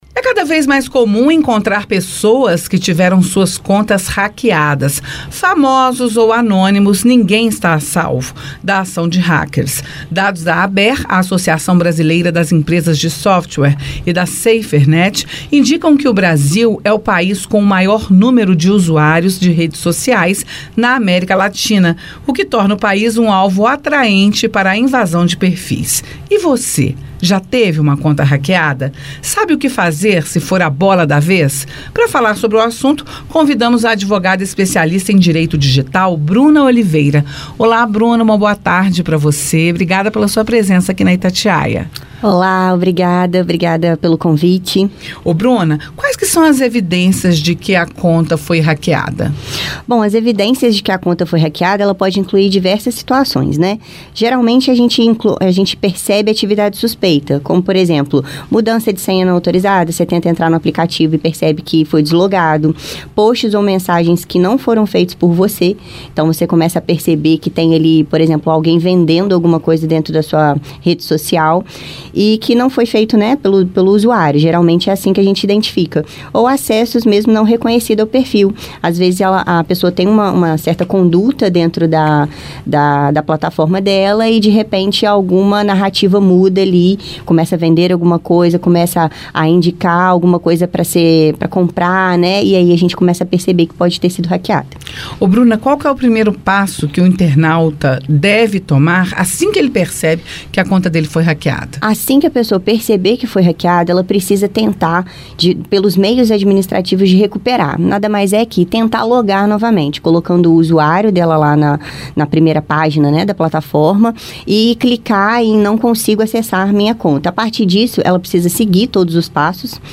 a advogada especialista em Direito Digital